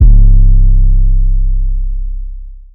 808 (Calabasas).wav